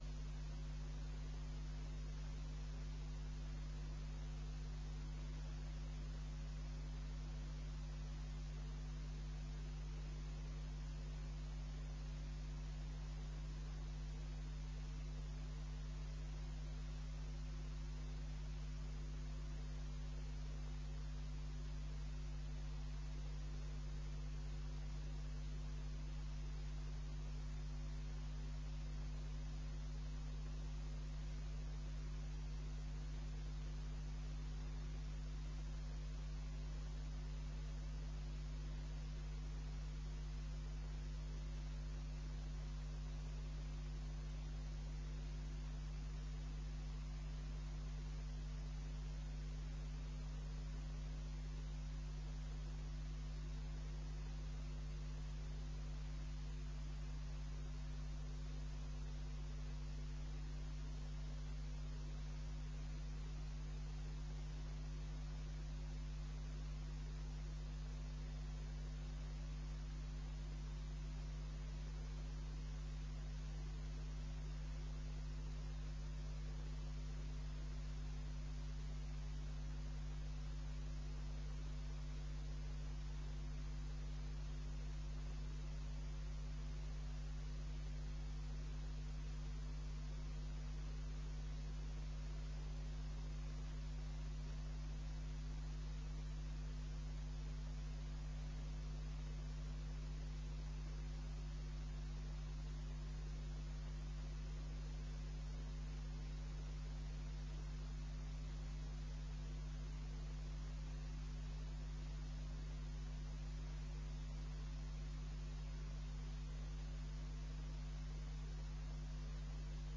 Informatiebijeenkomst 06 oktober 2009 19:00:00, Gemeente Tynaarlo
Locatie: Raadszaal